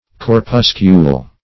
Corpuscule \Cor*pus"cule\ (k?r-p?s"k?l), n.